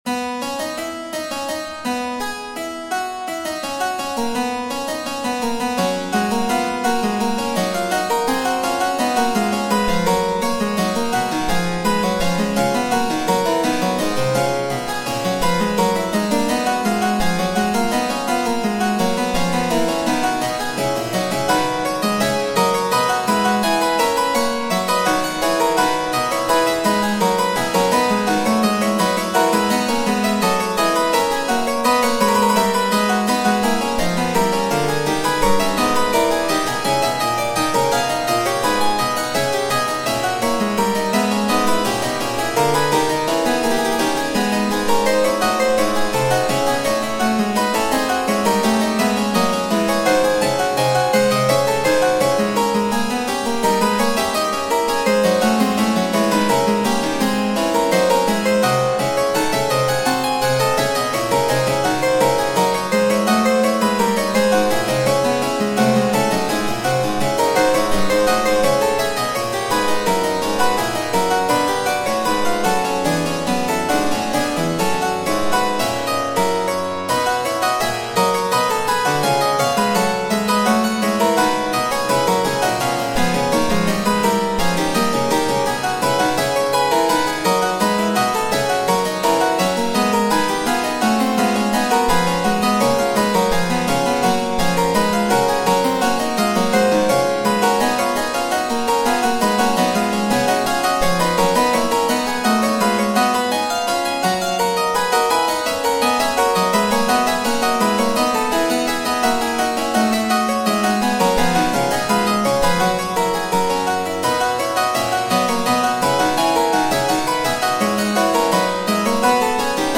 - Piano Music, Solo Keyboard - Young Composers Music Forum